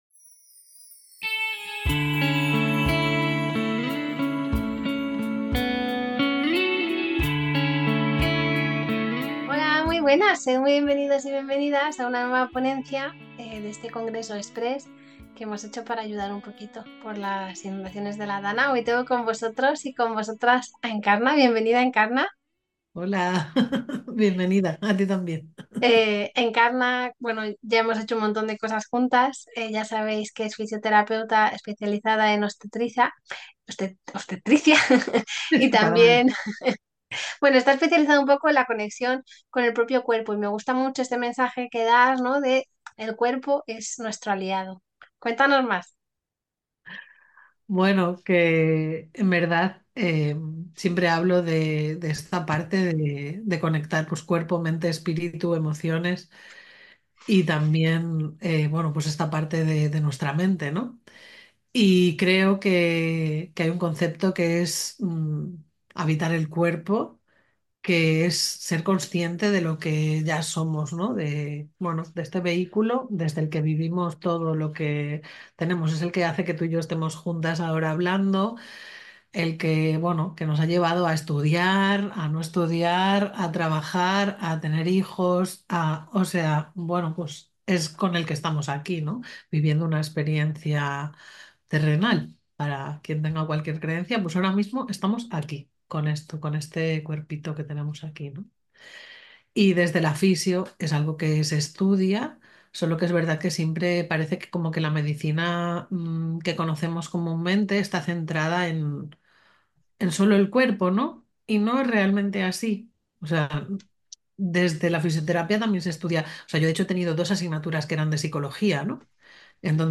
EL CUERPO COMO ALIADO En esta ponencia